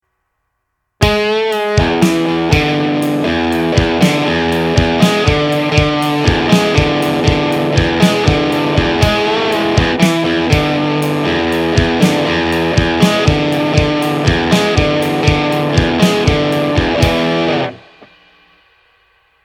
Rock guitar Riff
This rock riff starts with a slow bend that catches your attention followed by a droning droning open E with a melody played over top.  I love how it has a 90’s feel.
This rock guitar riff features a bend and several open E notes for a 90's feel.
LRT-031-Bending-Rock-Riff.mp3